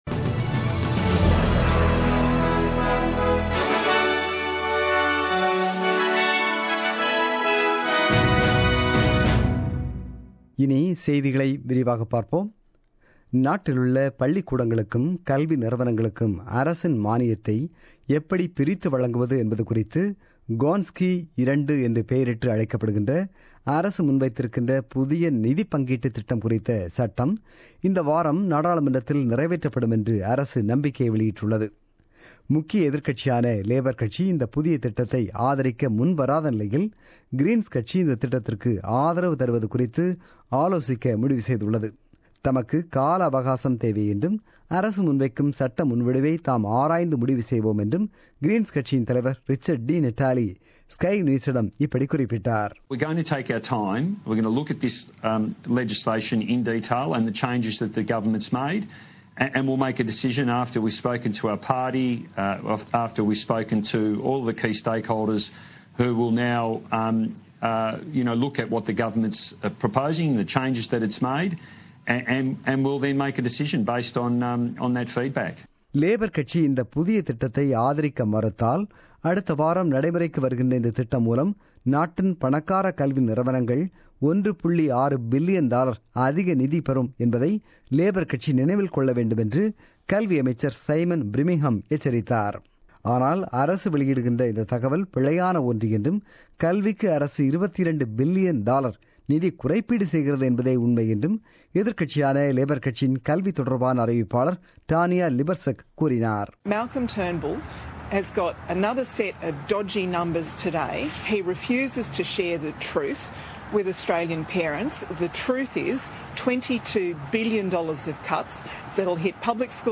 The news bulletin broadcasted on 18 June 2017 at 8pm.